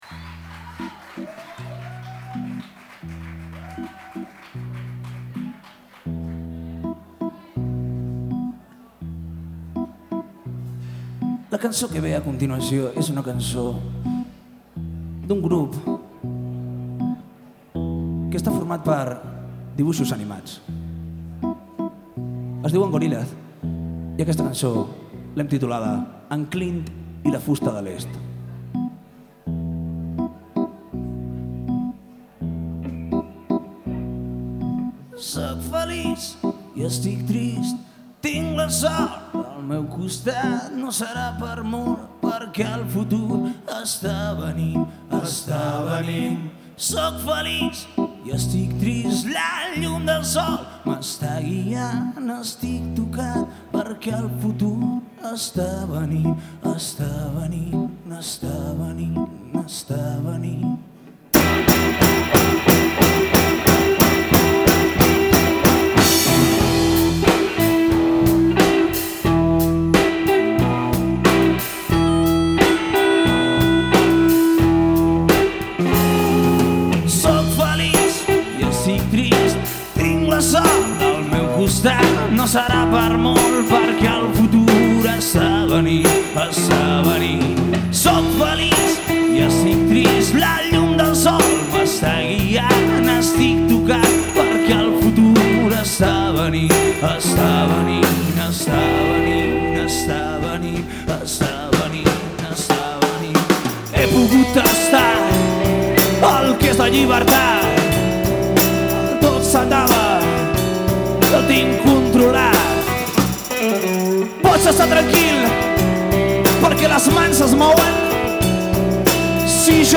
concert
versió al català